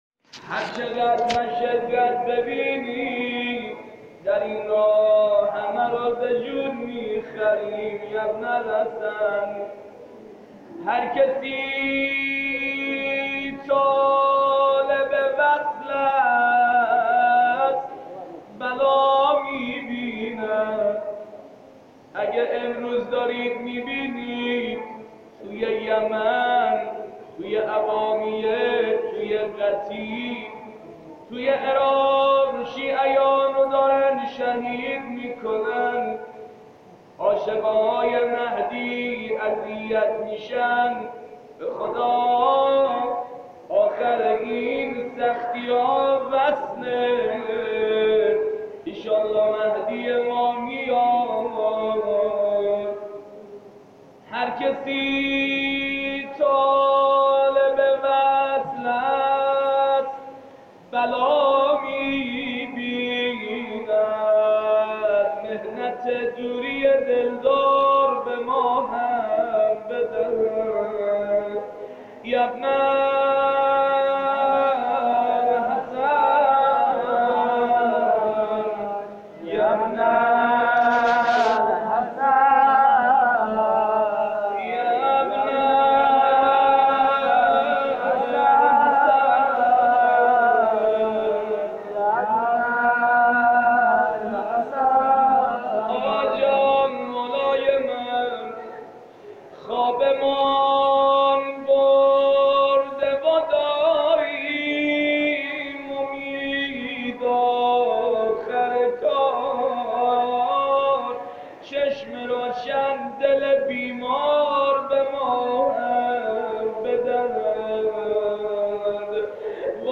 اولین مراسم دعای کمیل درماه مبارک رمضان2017
فرازی-ازدعای-کمیل.mp3